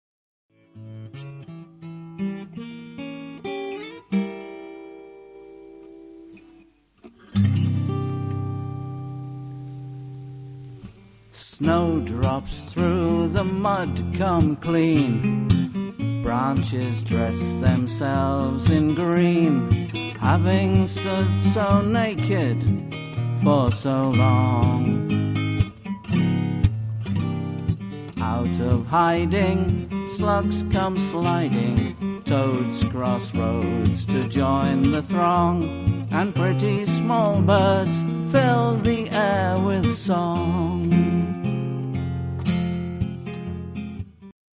lyricist vocalist humorist guitarist